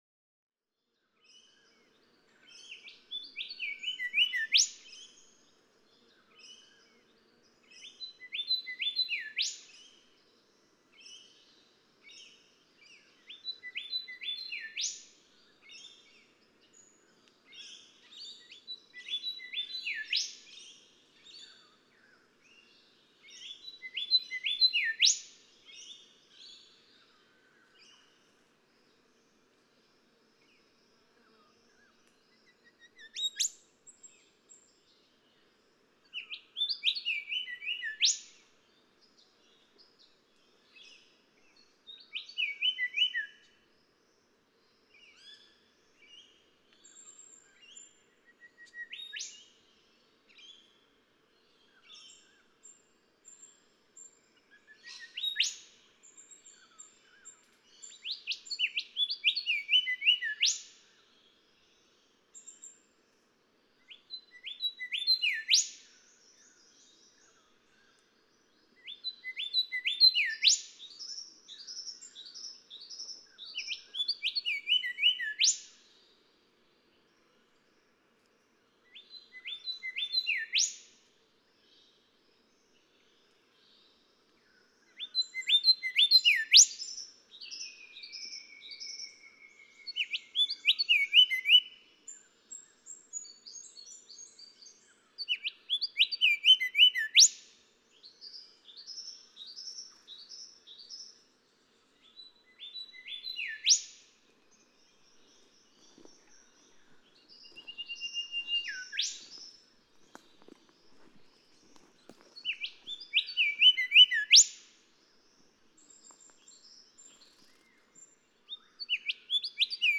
Golden whistler
What I find so striking in the songs of this bird, in addition to the stunning purity of the notes, is the similarity of these golden whistler songs to the whipcrack songs of the whipbird.
Goomburra Section of the Main Range National Park, Queensland.
A rufous fantail (see below) commandeers the microphone after 18 minutes.
729_Golden_Whistler.mp3